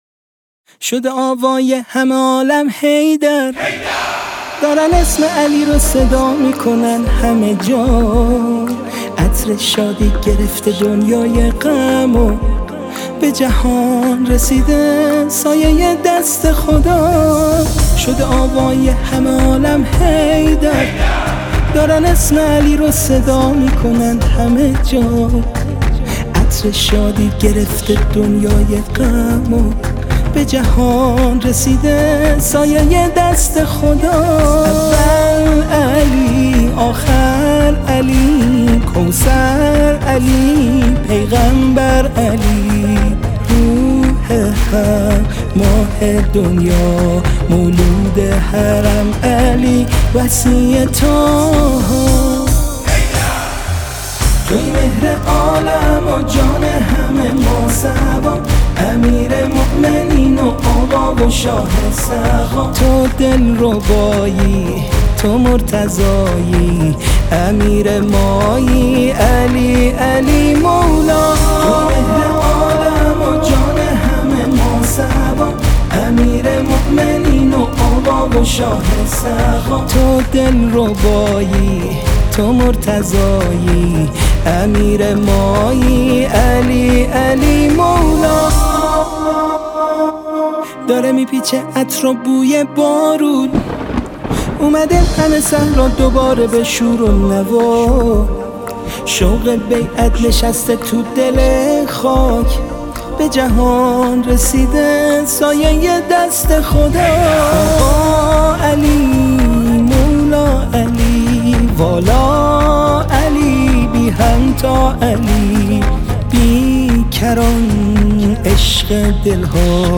تک آوا
شاد